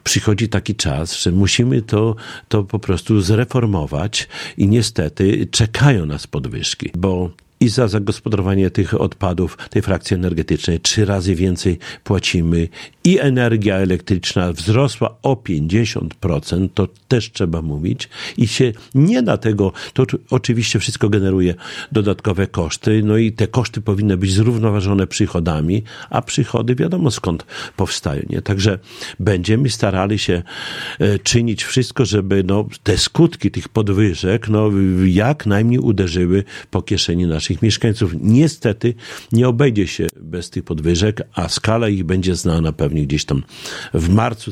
Jak mówi Czesław Renkiewicz, prezydent miasta, to nieuniknione. Wszystko przez wzrost kosztów utylizacji śmieci i podwyżki cen energii elektrycznej.
– Czekają nas podwyżki – mówi prezydent.